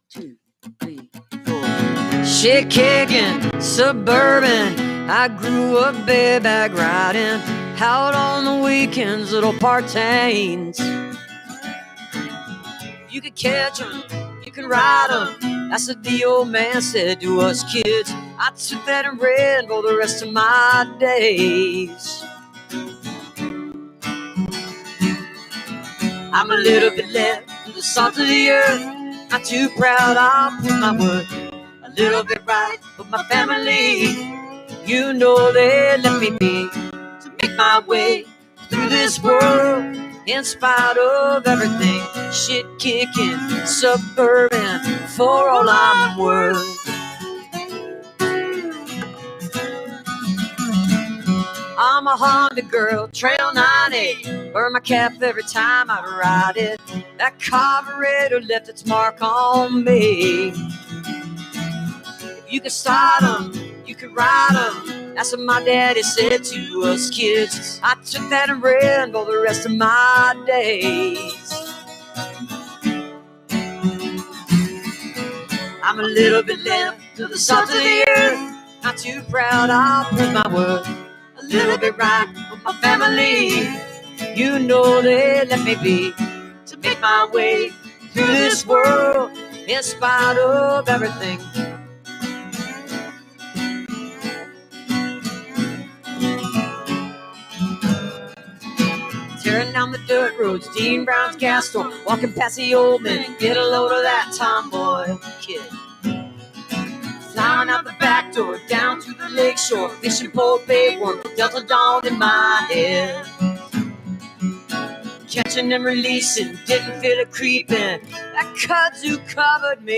(captured from the youtube livestream)